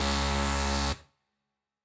В коллекции представлены различные варианты предупреждающих сигналов, блокировок и системных оповещений.
Звук недоступности